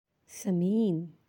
(samiin)